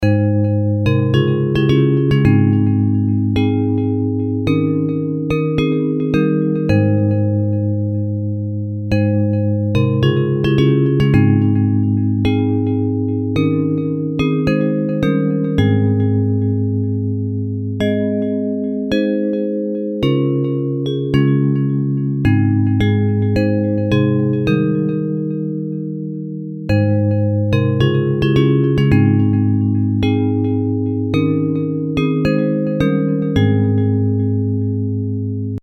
Hymn lyrics and .mp3 Download
Bells Version